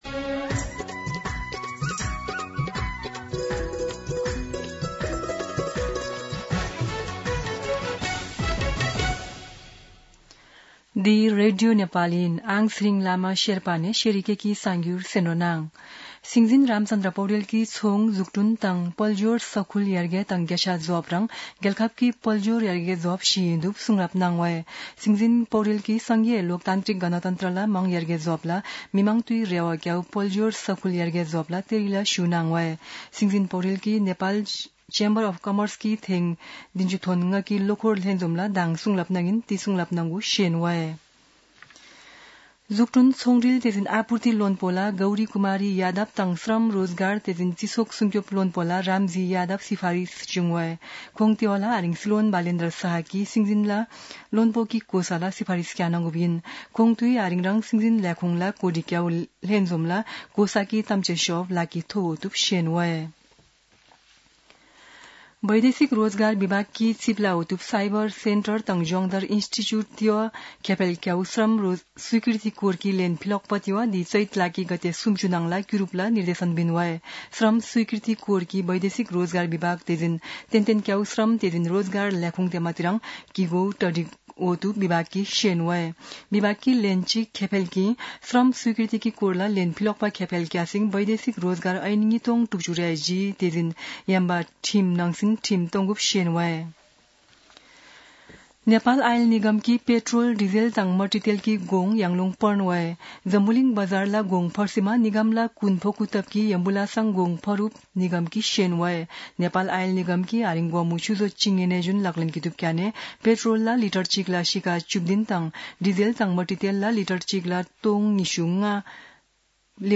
शेर्पा भाषाको समाचार : २७ चैत , २०८२
Sherpa-News-12-27.mp3